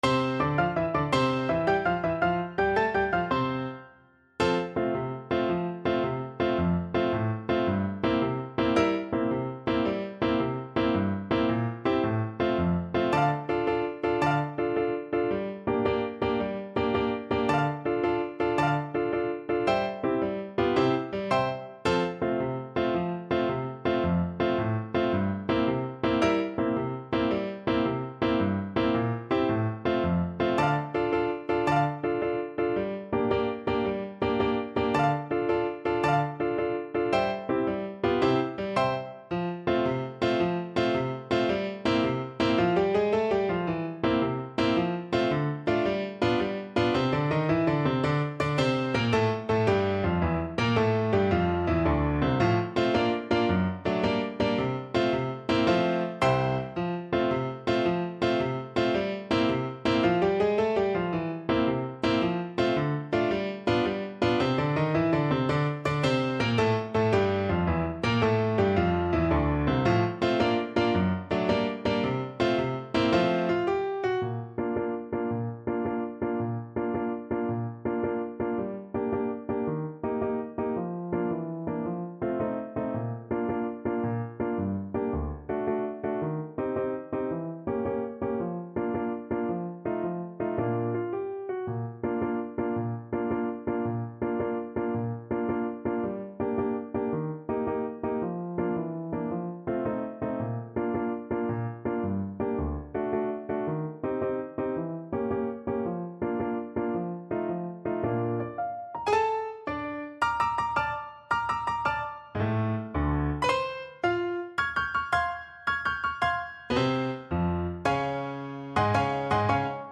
Play (or use space bar on your keyboard) Pause Music Playalong - Piano Accompaniment Playalong Band Accompaniment not yet available reset tempo print settings full screen
French Horn
6/8 (View more 6/8 Music)
F major (Sounding Pitch) C major (French Horn in F) (View more F major Music for French Horn )
Classical (View more Classical French Horn Music)